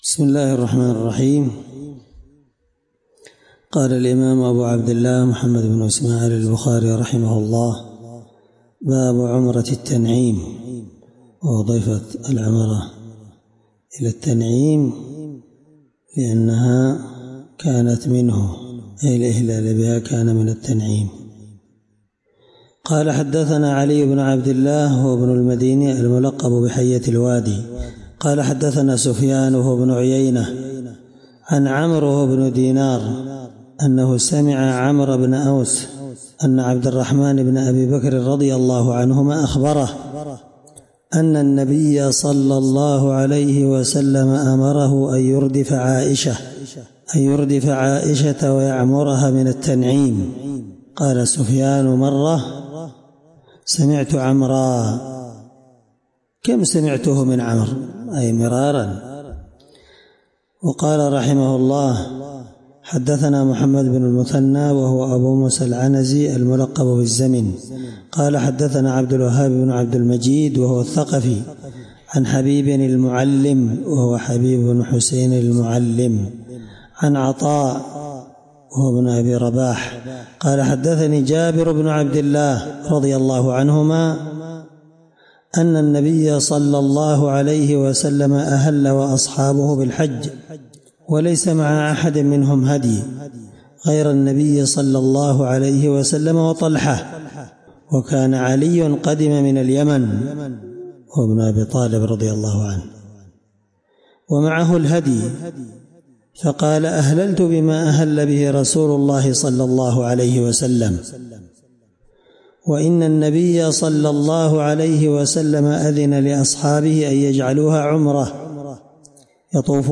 الدرس 5من شرح كتاب العمرة حديث رقم(1784-1785)من صحيح البخاري